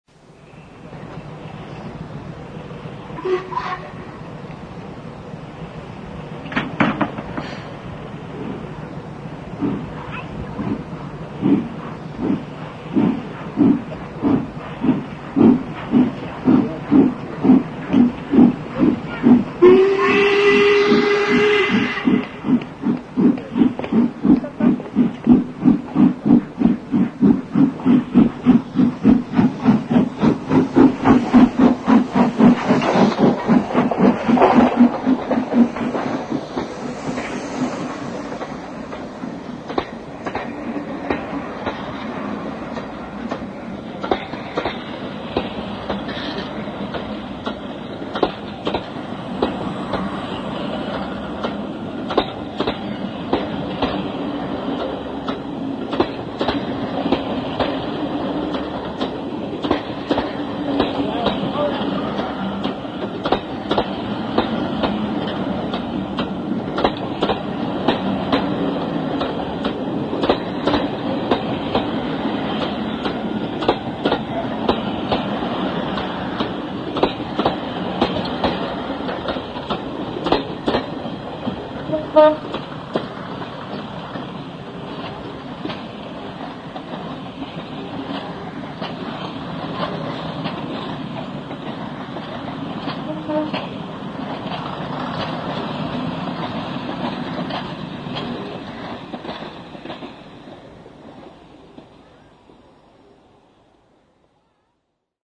One of my earliest recordings (with a mono cassette recorder) was of 'Scots Guardsman' a loco which I first saw stored at Carlisle Kingmoor then later at Haworth and Dinting from where it managed a couple of main line outings before disappearing from the main line scene with little likelyhood of returning, or so we thought...
On 11th November 1978 I spent a day at York where I recall, it was very foggy.